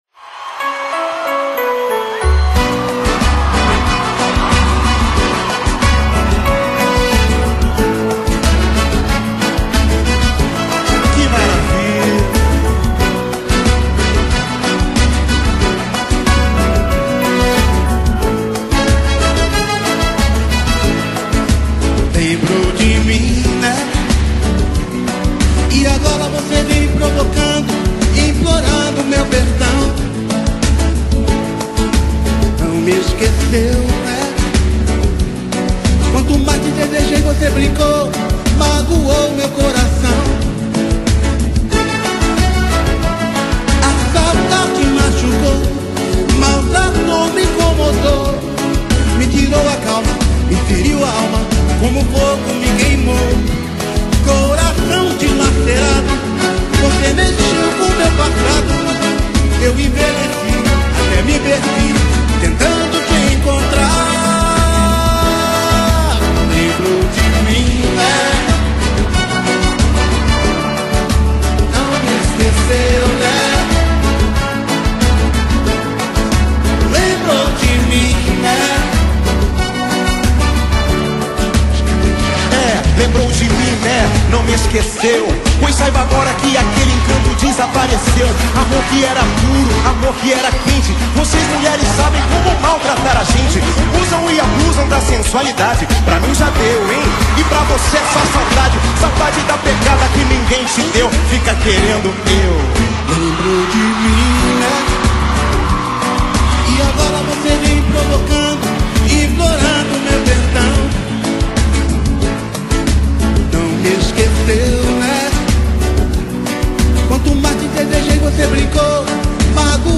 Samba Para Ouvir: Clik na Musica.